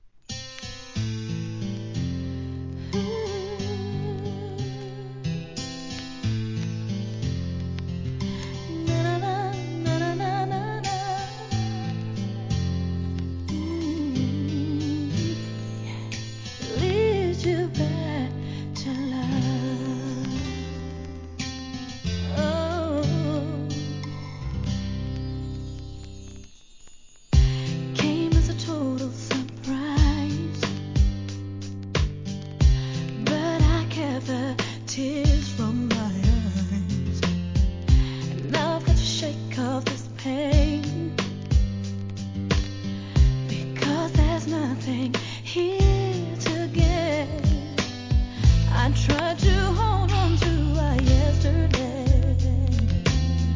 HIP HOP/R&B
全体を通し、しっとりと聴き入れられる1999年アルバム♪